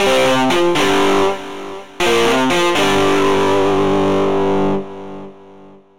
synth guitar
Aj na subtractore sa da nagenerovat umela gitara pri trochu snazeni sa, hoc to znie furt velmi umelo a velmi nie verne...ale nic lepsie synteticke som nepocul :-)
synth_guitar_dist_03.mp3